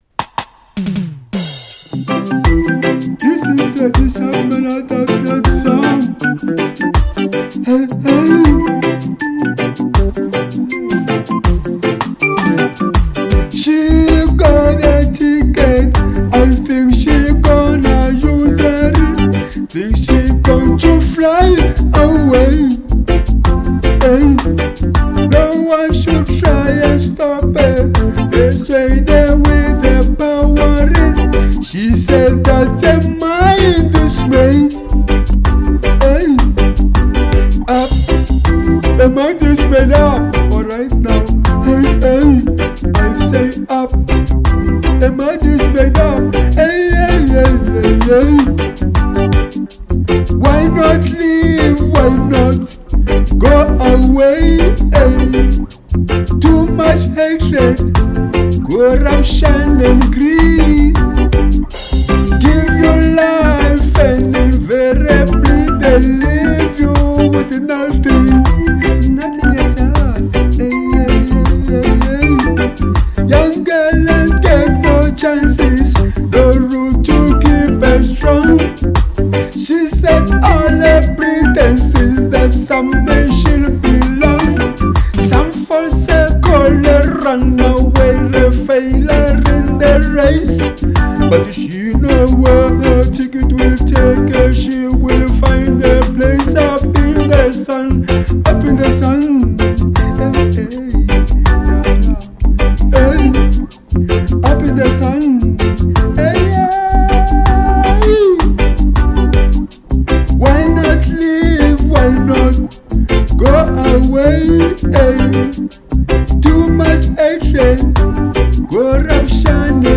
NEW reggae music!